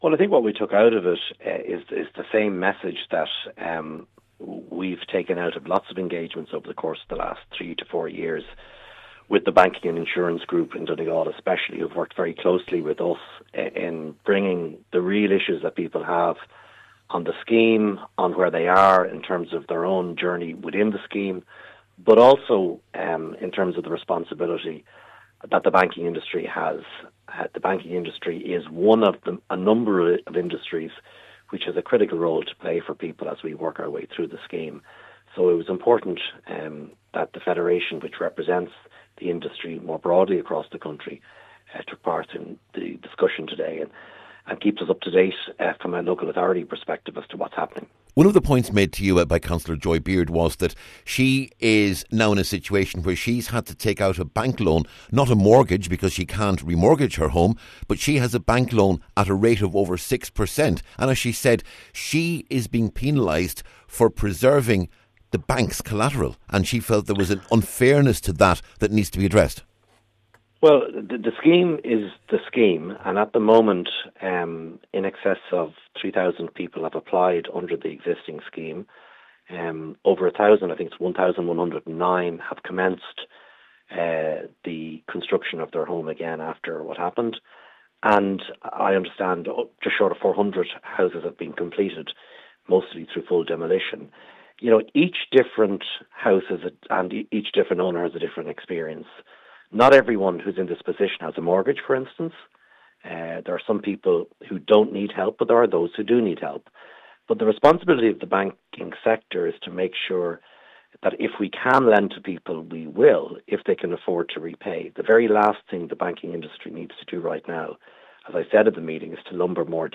(Interview in Full)